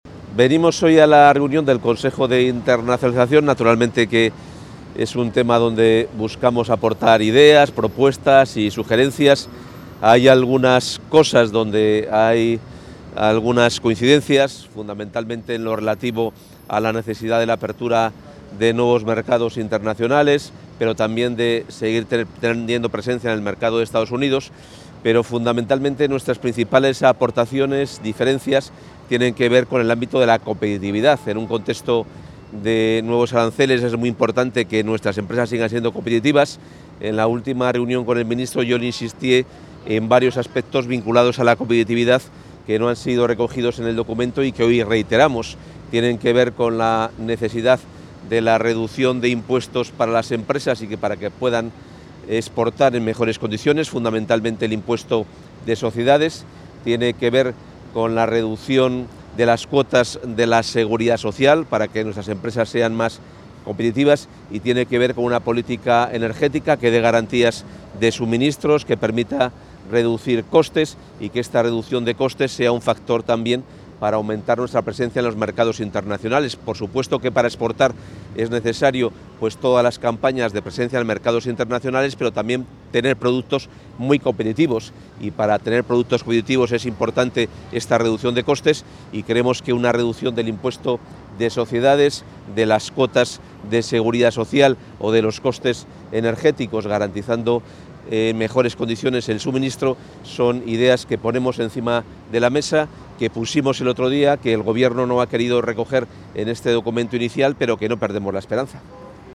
Declaraciones del consejero de Economía y Hacienda previas al Consejo Interterritorial de Internacionalización
El consejero de Economía y Hacienda, Carlos Fernández Carriedo, asiste hoy al Consejo Interterritorial de Internacionalización. A su llegada a la sede del Ministerio de Economía, Comercio y Empresa en Madrid, el también portavoz de la Junta ha realizado unas declaraciones previas.